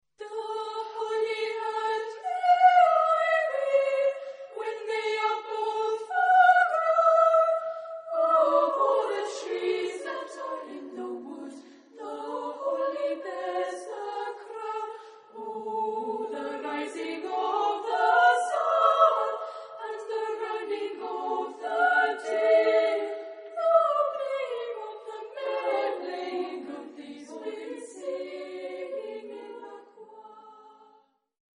Genre-Style-Forme : Chant de Noël
Tonalité : la majeur ; mi majeur